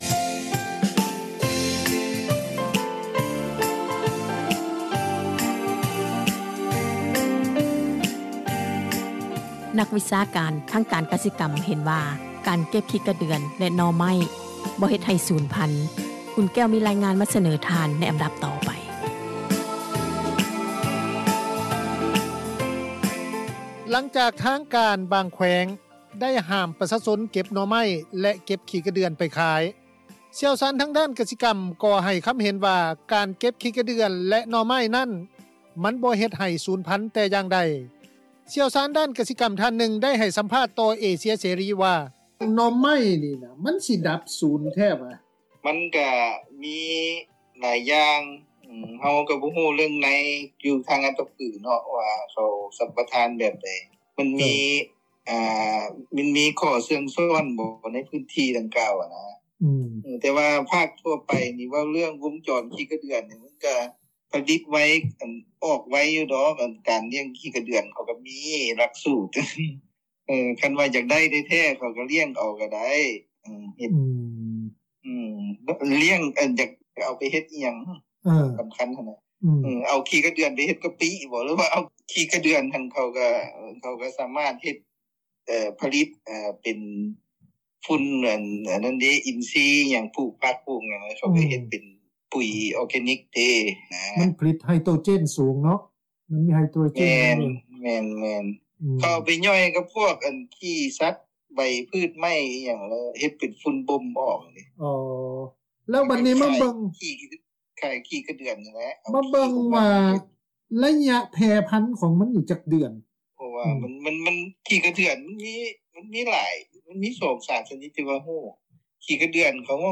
ຜູ້ຊ່ຽວຊານ ດ້ານກະສິກັມທ່ານນຶ່ງ ໄດ້ໃຫ້ສໍາພາດຕໍ່ວິທຍຸ ເອເຊັຽເສຣີ ວ່າ: